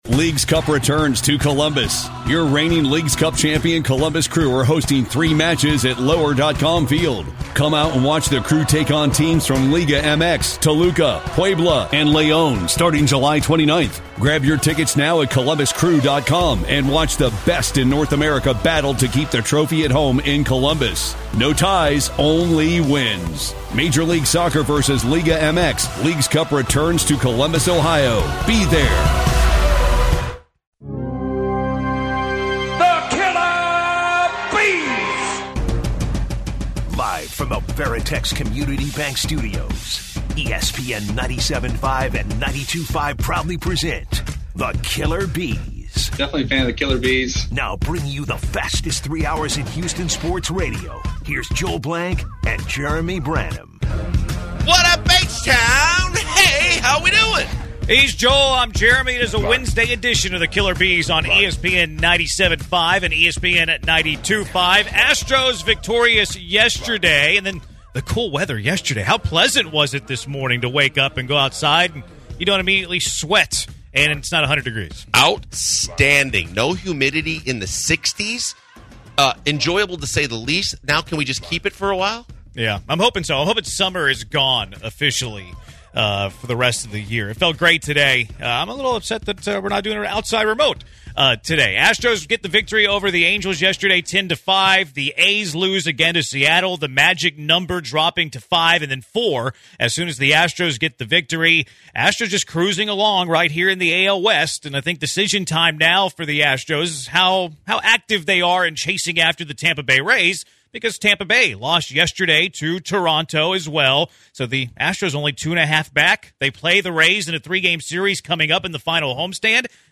The Astros are rolling and Jose Urquidy has a good outing. We hear from Dusty Baker on Urquidy's start which leads to a discussion on the playoff rotation.